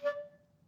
Clarinet
DCClar_stac_D4_v1_rr2_sum.wav